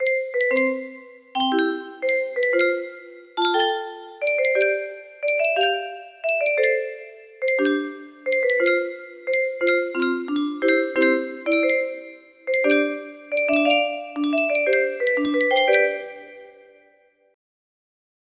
clone, fixed pacing :^))